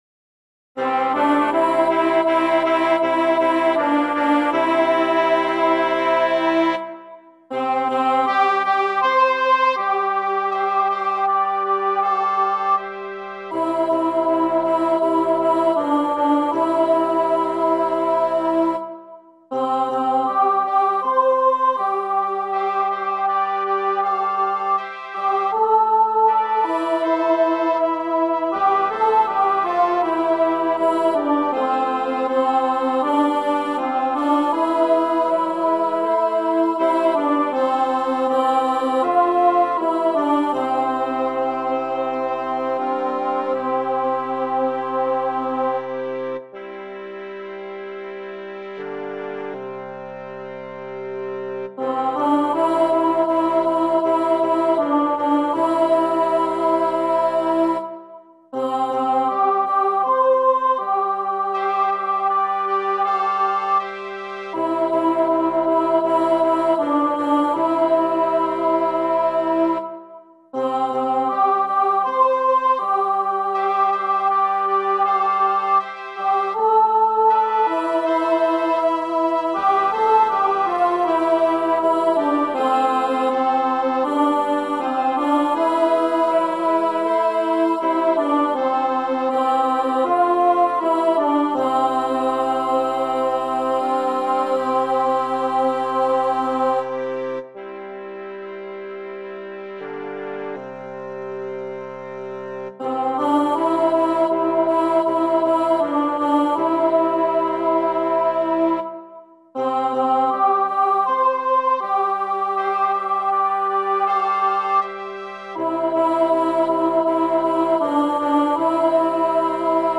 practice sound-clip